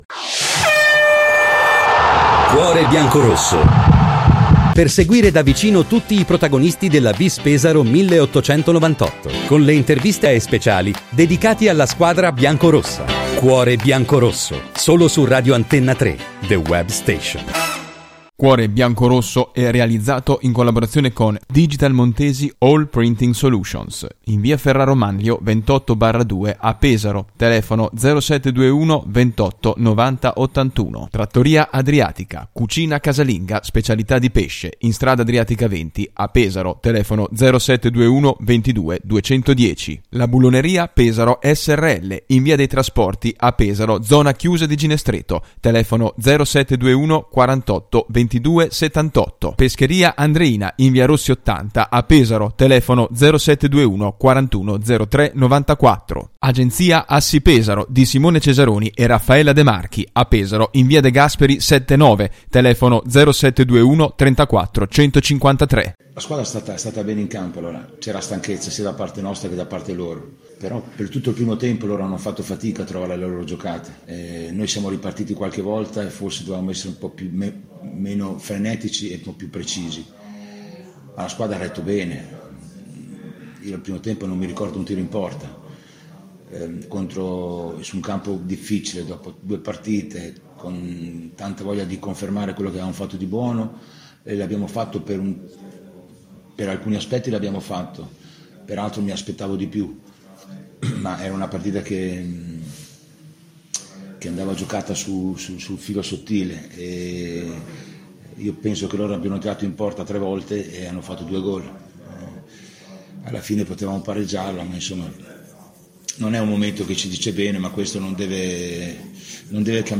“Cuore Biancorosso”: Le Interviste